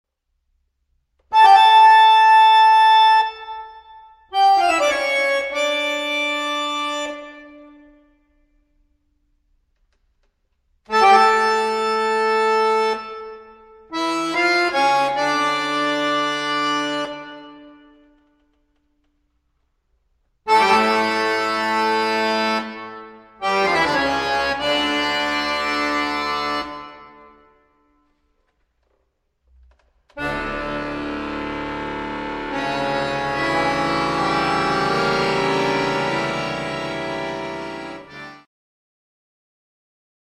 Toccata